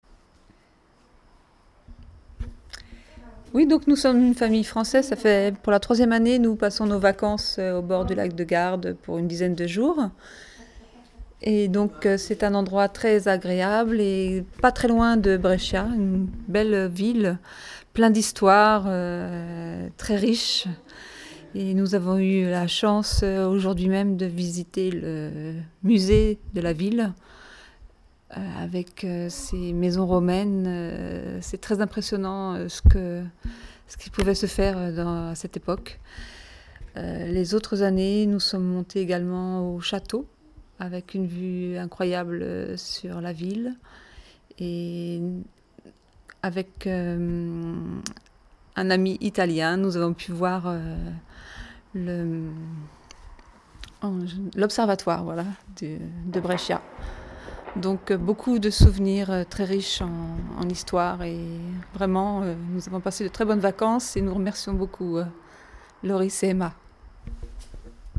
l’interview.